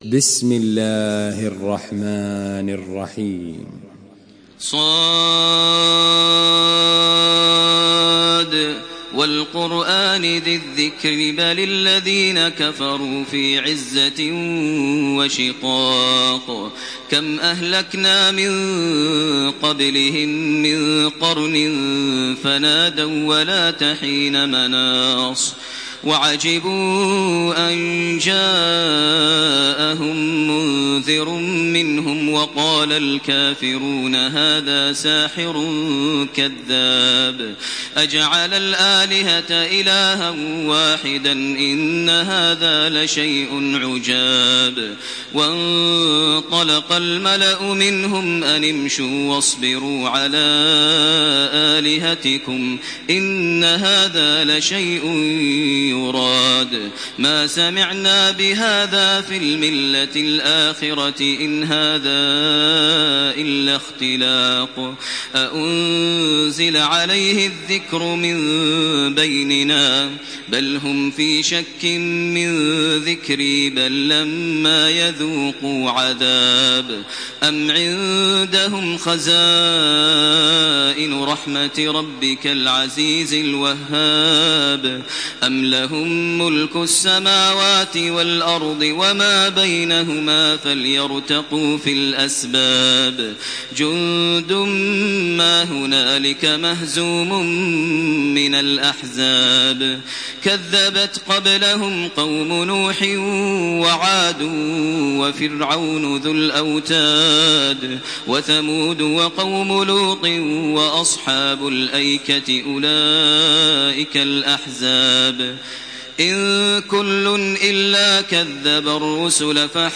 تحميل سورة ص بصوت تراويح الحرم المكي 1428
مرتل حفص عن عاصم